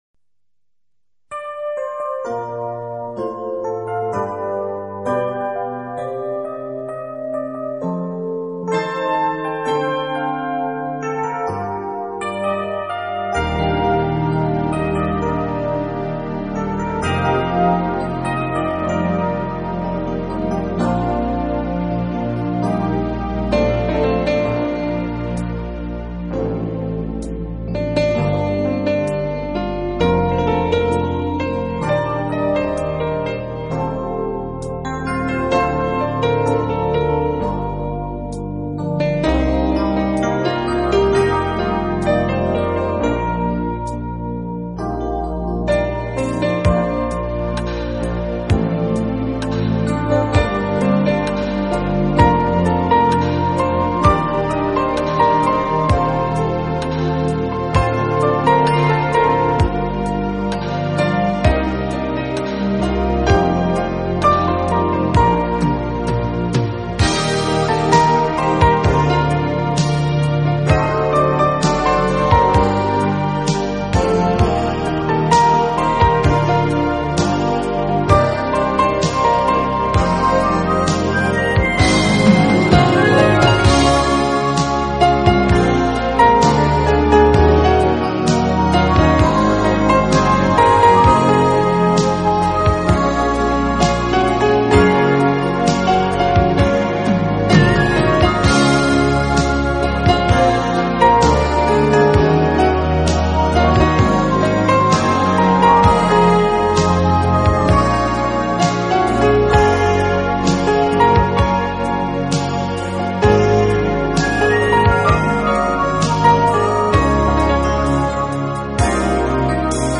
Genre: Instrumental
Quality: MP3 / Joint Stereo
这又是一张非常经典的老曲目经过改编用钢琴重新演绎的专辑。
本套CD全部钢琴演奏，